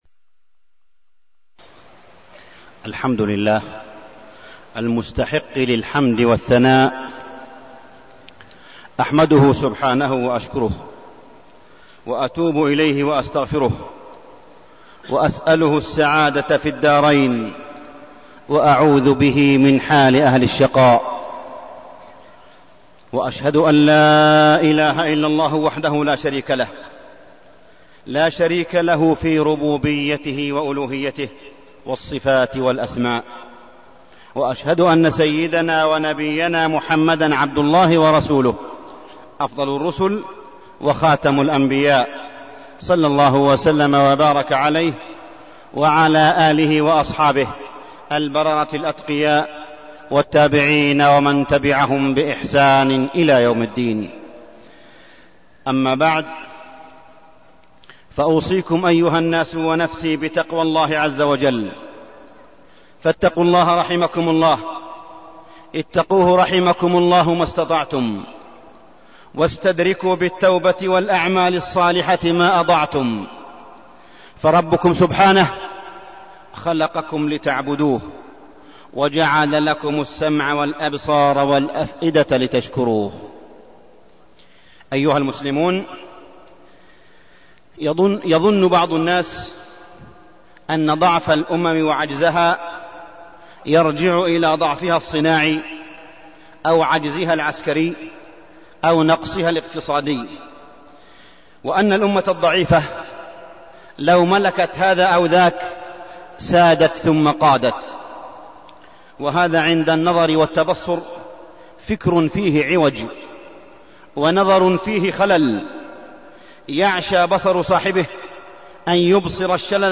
تاريخ النشر ٢٠ جمادى الآخرة ١٤١٧ هـ المكان: المسجد الحرام الشيخ: معالي الشيخ أ.د. صالح بن عبدالله بن حميد معالي الشيخ أ.د. صالح بن عبدالله بن حميد دعوة تحرير المرأة